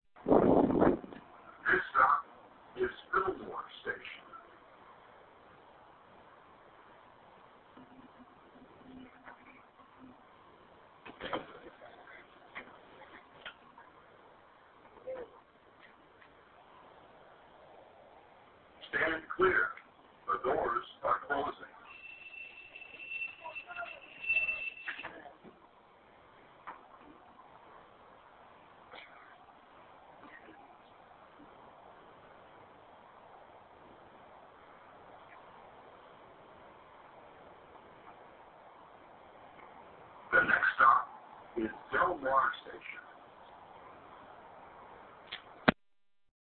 Here's the sound of the automated Gold Line stop announcements.